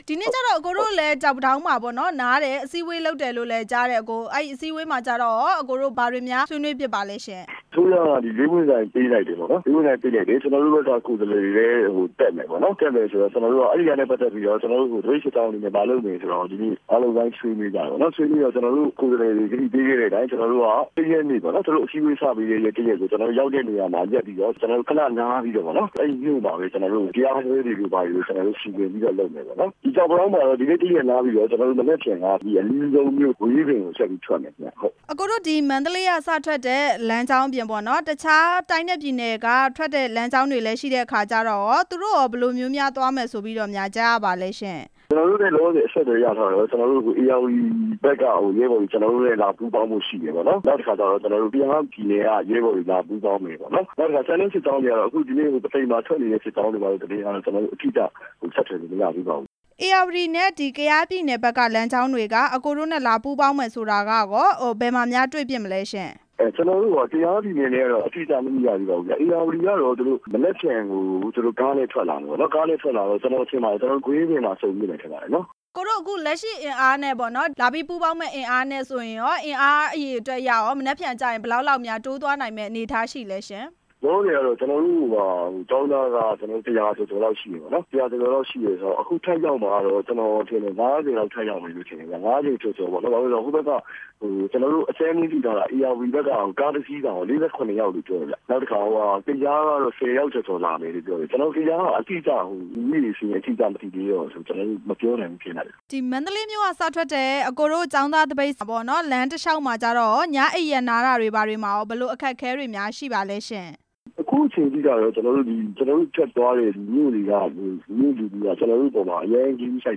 မန္တလေးမြို့က ဆန္ဒပြ ကျောင်း သားသပိတ်နဲ့ ဧရာဝတီတိုင်း က ကျောင်းသားတွေ ပူးပေါင်း မယ့်အကြောင်း မေးမြန်းချက်